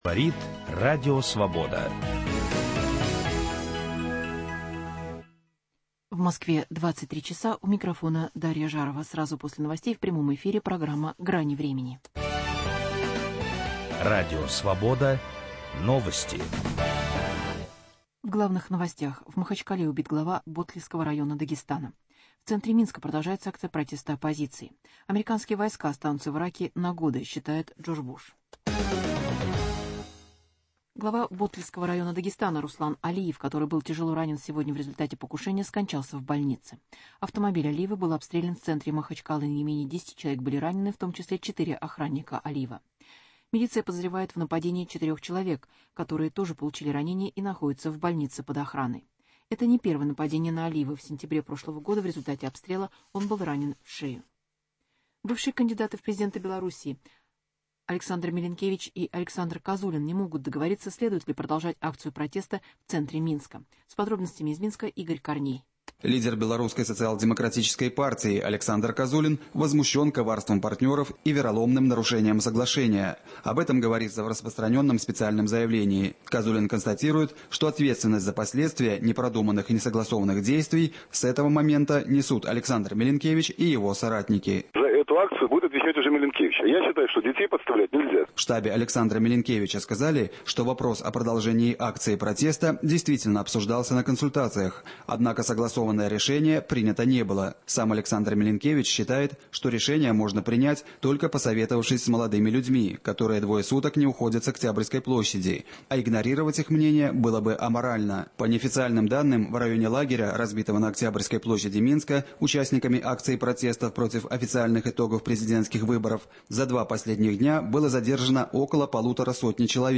Проблемы российско-китайского сотрудничества. Гость – Борис Резник, зампред комитета Госдумы по информационной политике, член фракции «Единая Россия»